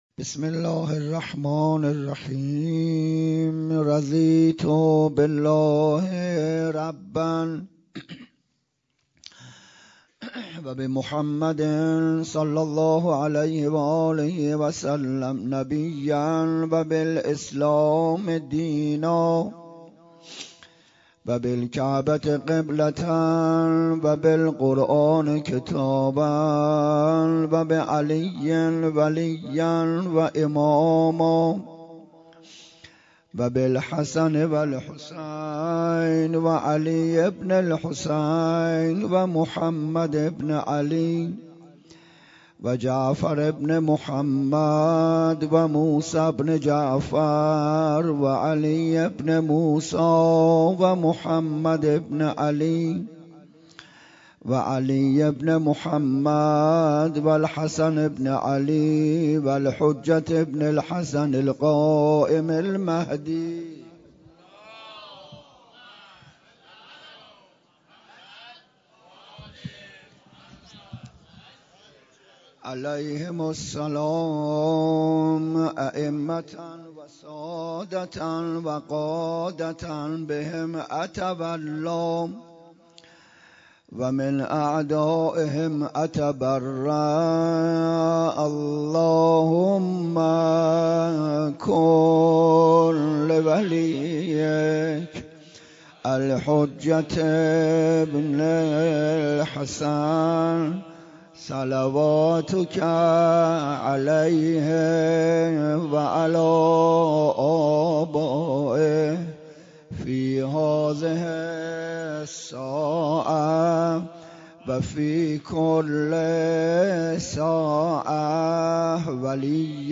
11 بهمن 96 - مسجد عشقعلی - پاسخ به شبهات وهابیت
سخنرانی